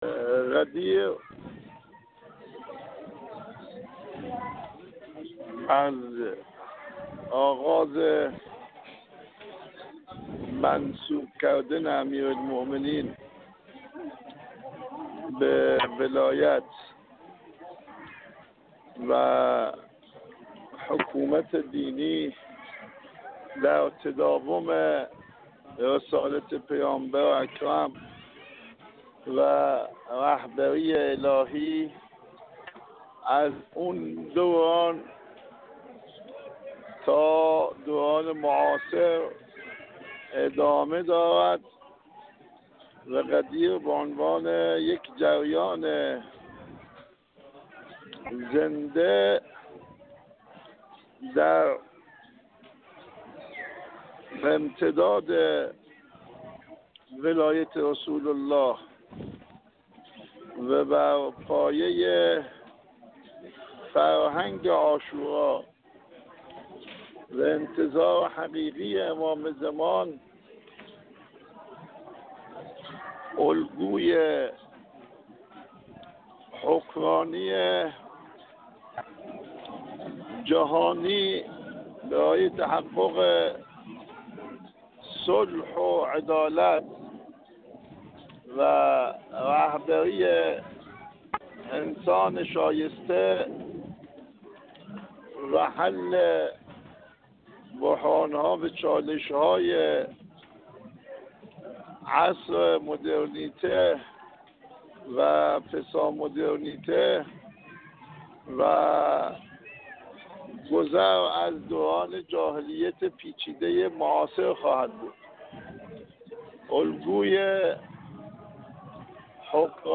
آیت‌الله عباس کعبی، عضو هیئت رئیسه مجلس خبرگان رهبری
آیت‌الله عباس کعبی، عضو هیئت رئیسه مجلس خبرگان رهبری، در گفت‌وگو با ایکنا درباره پیام غدیر و ویژگی‌های حکمرانی علوی گفت: غدیر از آغاز منصوب کردن امیرالمؤمنین علی(ع) به ولایت و حکومت دینی در تداوم رسالت پیامبر اکرم(ص) و رهبری الهی است که از آن دوران تا دوران معاصر ادامه دارد.